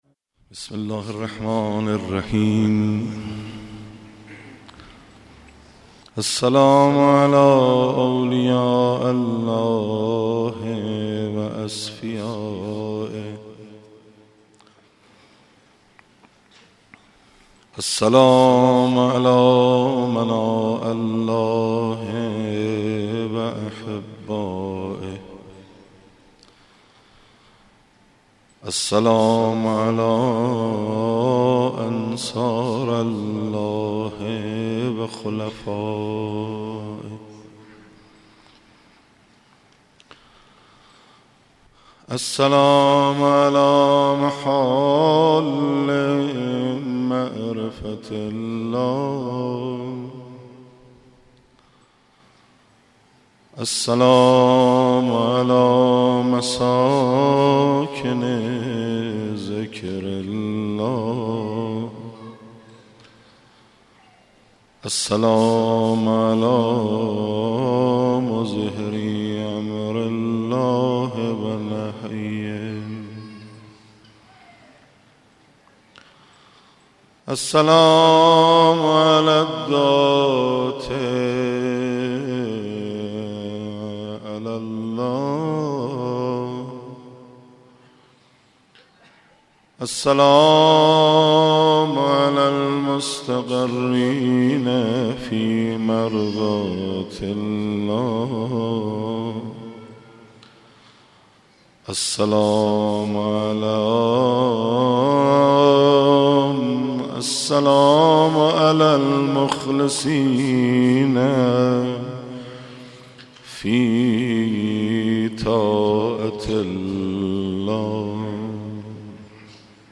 مناسبت : ولادت حضرت مهدی عج‌الله تعالی‌فرج‌الشریف
قالب : مناجات